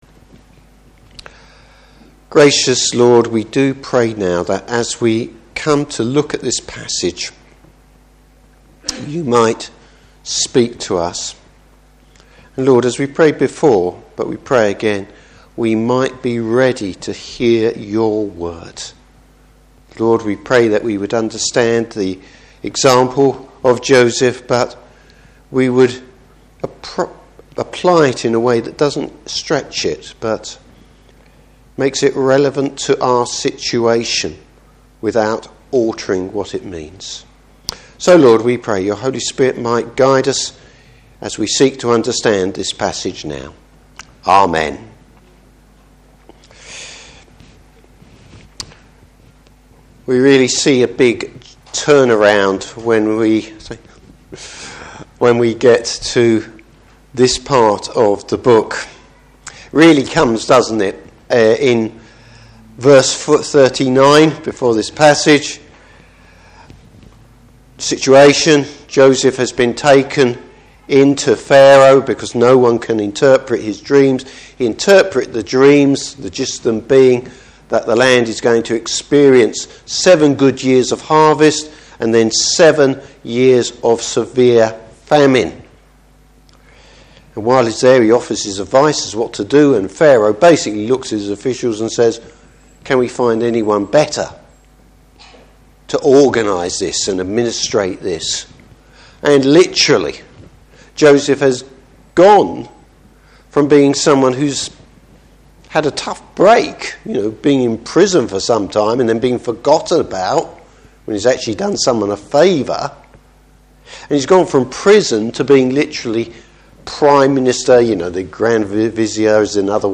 Service Type: Evening Service Joseph starts to put his gift to work.